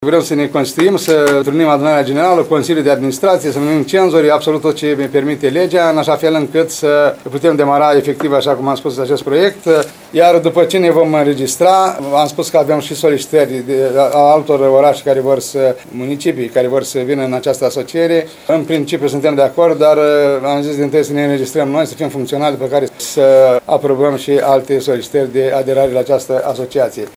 Primarul ION LUNGU a precizat astăzi că sediul va fi la Iași, orașul cel mai mare dintre cele 7 care compun asociația.